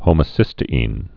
(hōmə-sĭstə-ēn, -ĭn, -tē-)